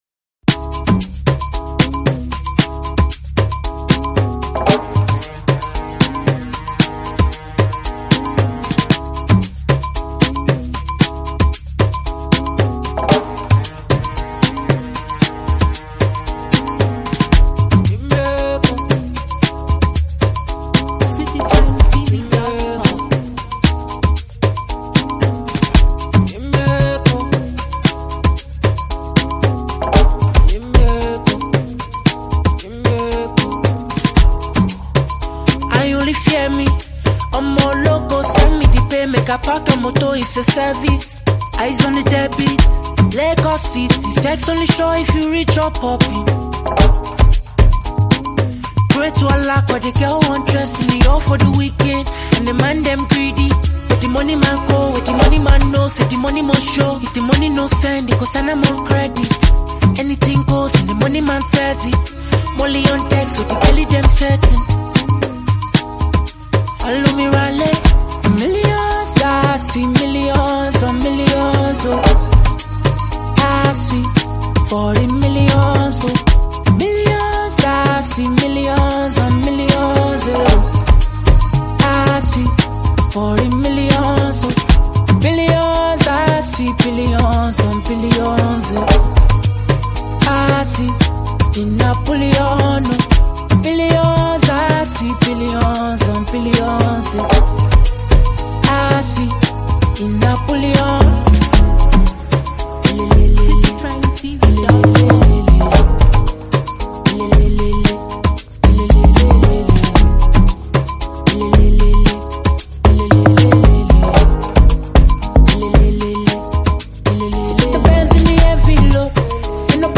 Renowned Nigerian Afrobeats talent and performer
It’s a song packed with depth, melody, and pure creativity.